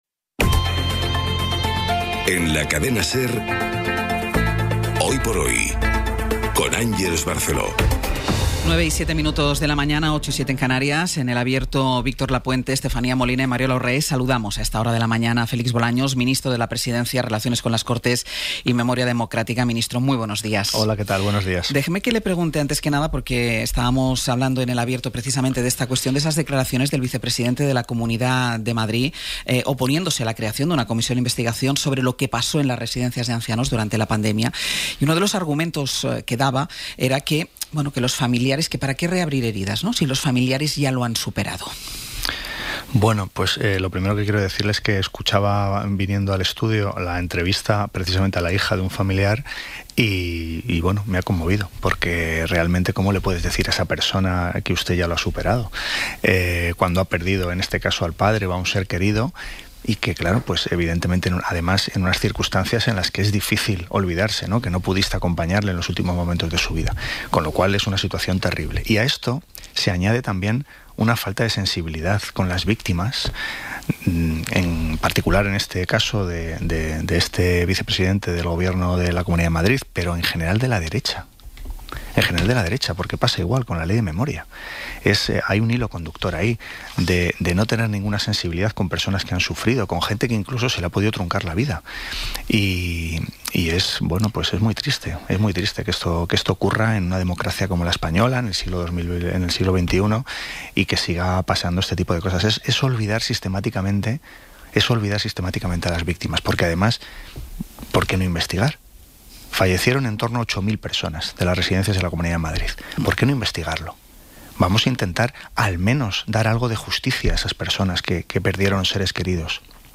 El ministro de la Presidencia, Relaciones con las Cortes y Memoria Democrática, Félix Bolaños, ha pasado este jueves por los micrófonos de Hoy por Hoy, con Àngels Barceló, después de que este miércoles se aprobara de forma definitiva la ley de Memoria Democrática que declara ilegal la dictadura franquista con el voto en contra del PP, Vox y Ciudadanos.